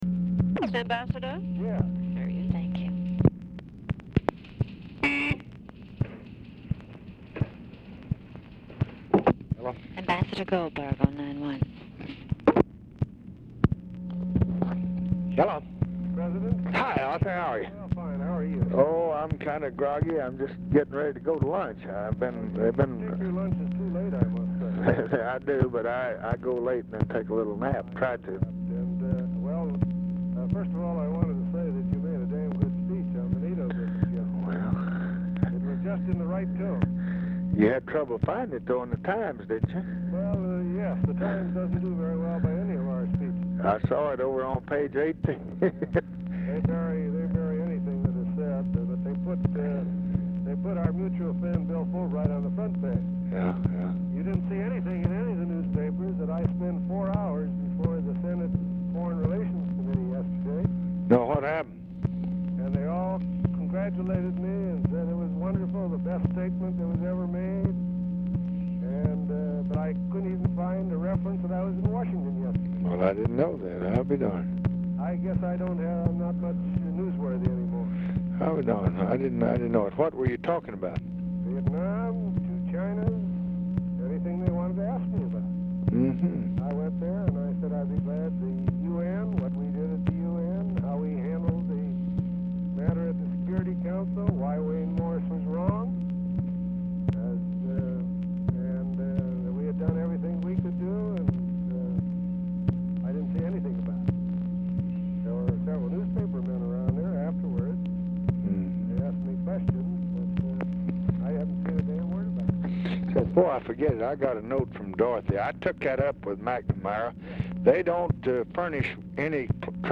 Telephone conversation # 9911, sound recording, LBJ and ARTHUR GOLDBERG
POOR SOUND QUALITY; GOLDBERG IS DIFFICULT TO HEAR
Dictation belt